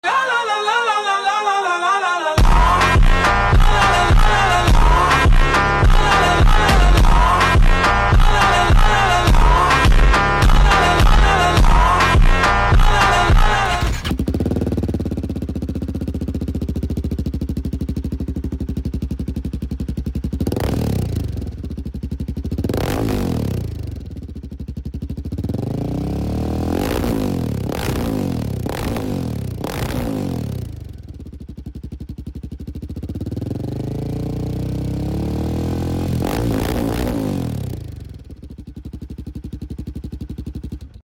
Ecco come ruggisce la Royal Enfield Himalayan 450 con il nostro scarico GPR Duplex 🔥 Pronto a sentire la differenza?